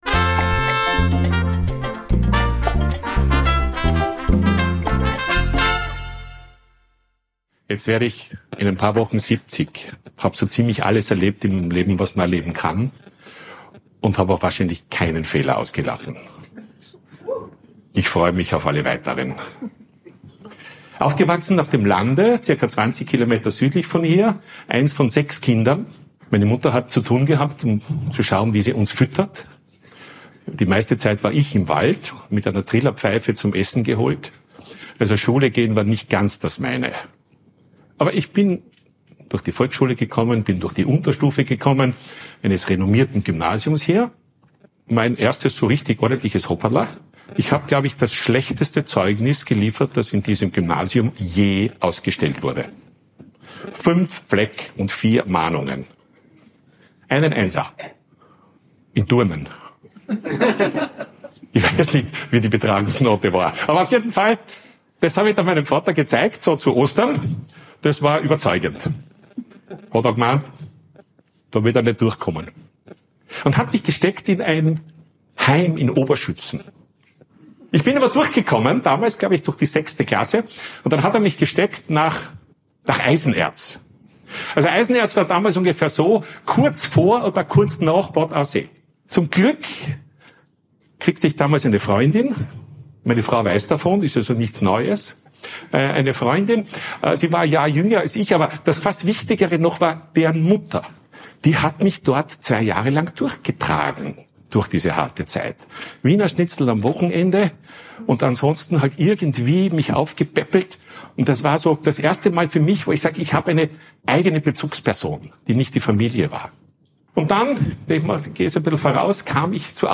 Von Rückschlägen und Ausrutschern: Mein Vortrag bei der Fuckup Night in Graz